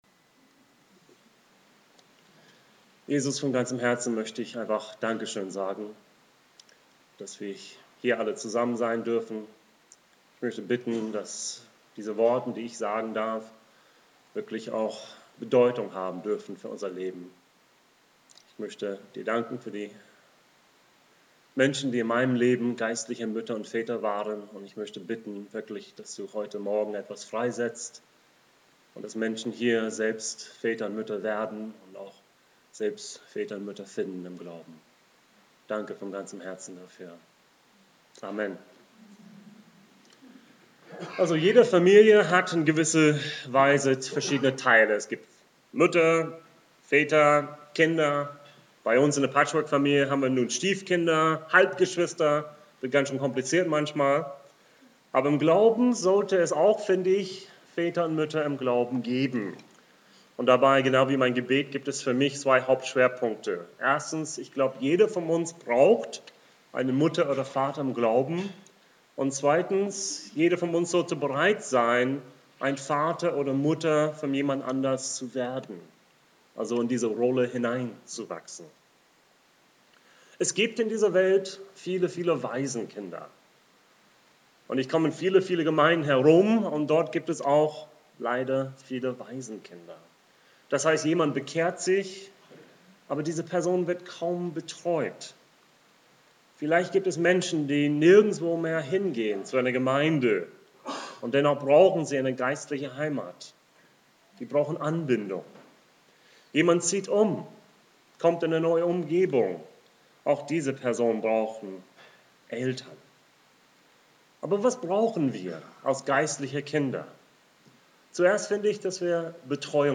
Hier kannst du die Predigt von Flensburg hören